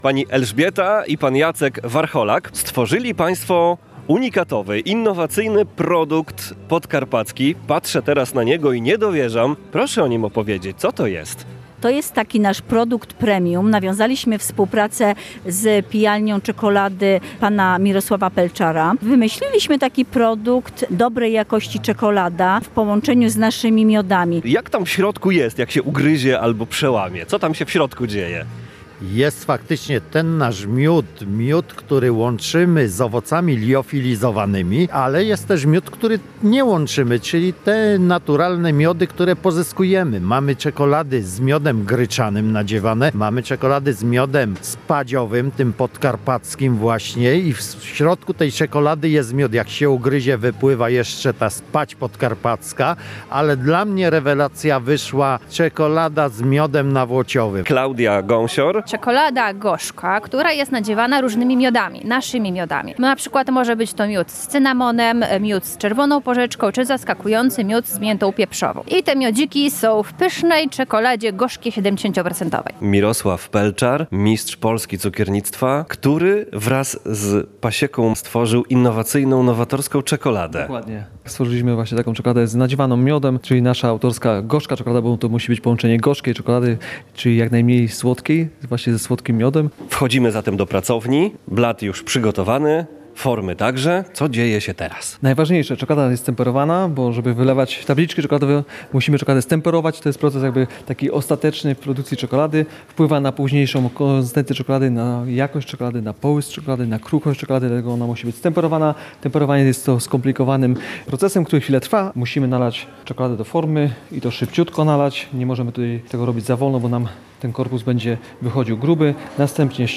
Z twórcami czekolady rozmawiał